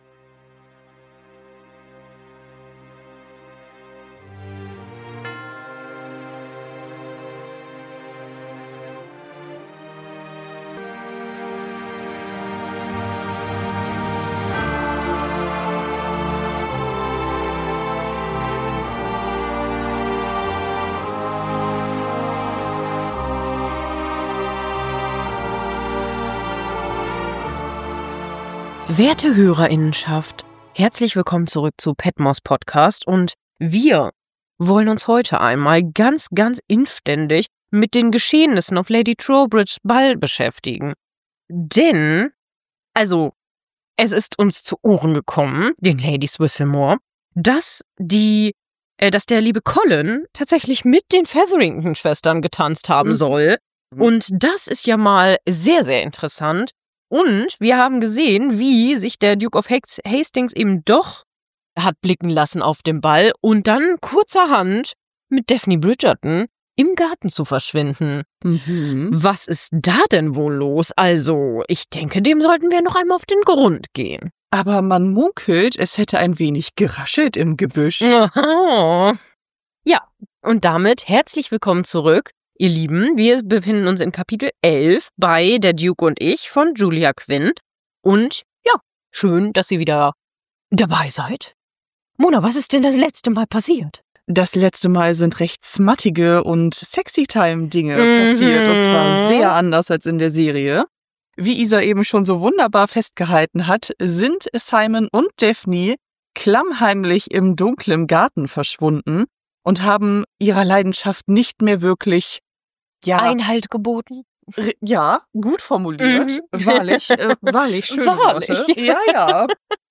Die Podcasterinnen machen sich doch nun erstlich Sorgen um den Ruf und das Urteilsvermöge der guten Miss Bridgerton.